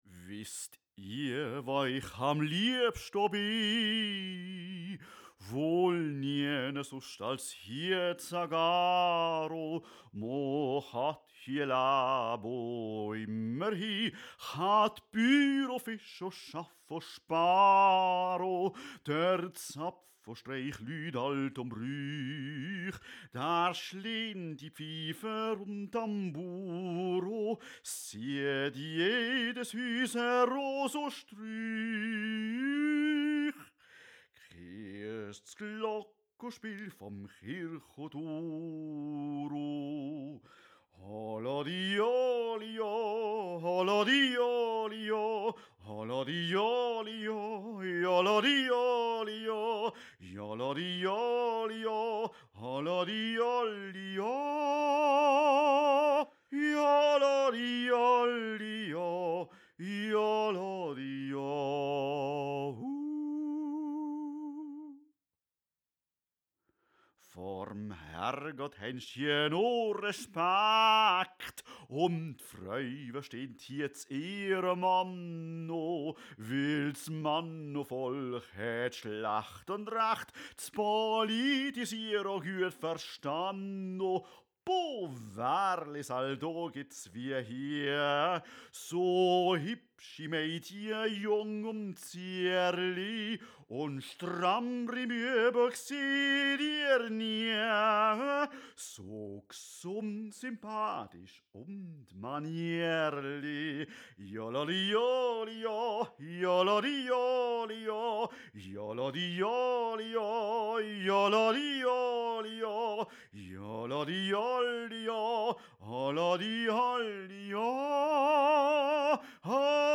Hauptstimme solo